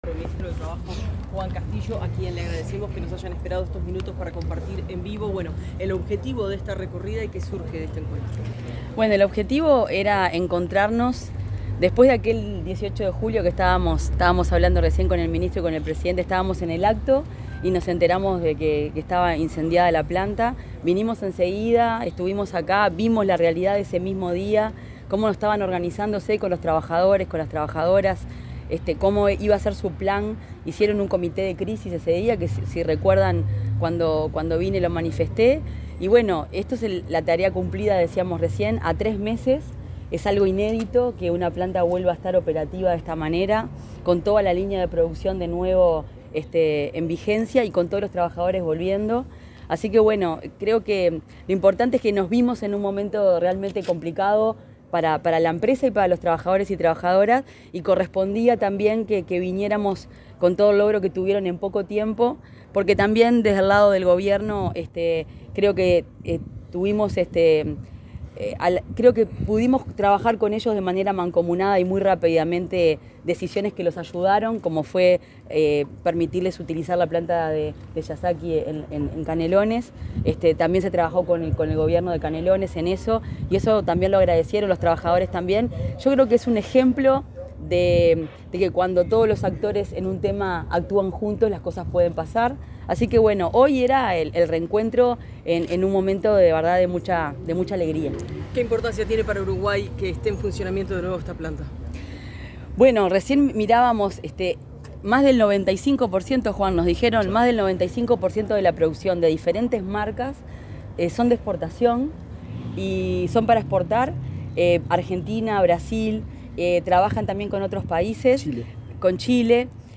Declaraciones de ministros de Industria y Trabajo, Fernanda Cardona y Juan Castillo
Tras una visita a la planta de Nordex ubicada en el barrio Colón, de Montevideo, a la cual también asistió el presidente de la República, Yamandú Orsi